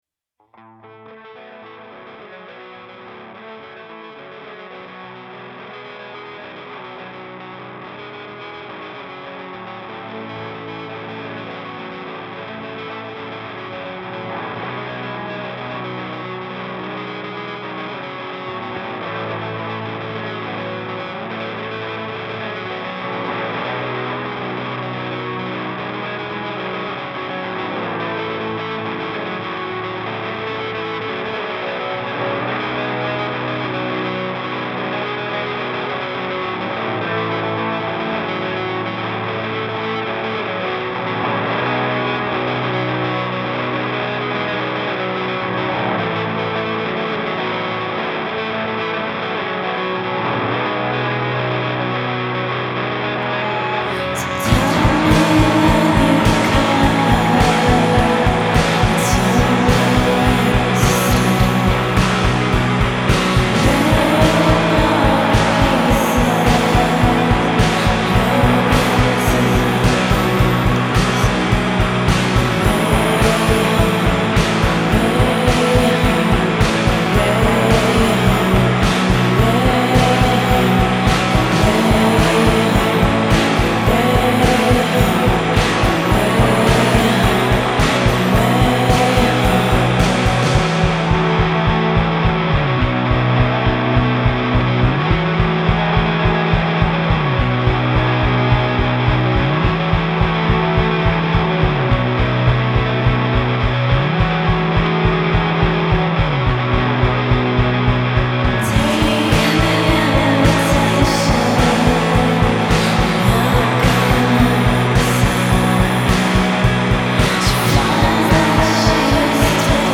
After you listen…realize it’s only a duo.